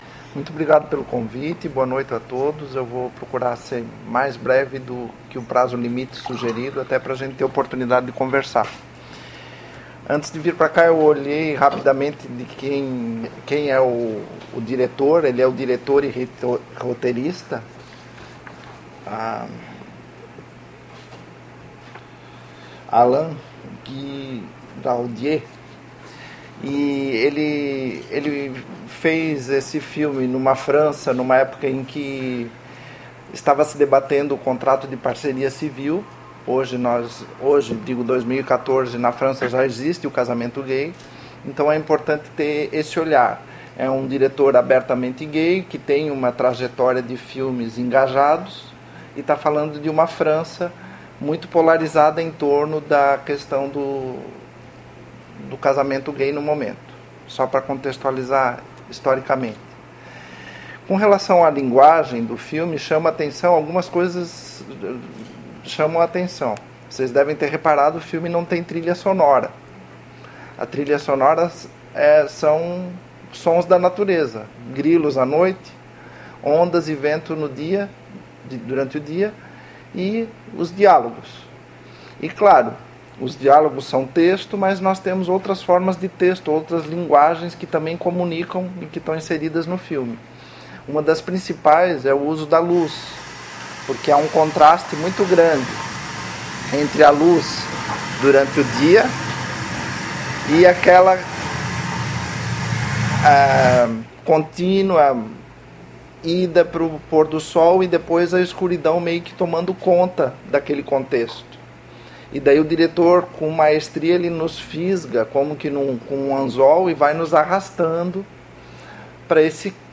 realizada em 09 de outubro de 2014 no Auditório "Elke Hering" da Biblioteca Central da UFSC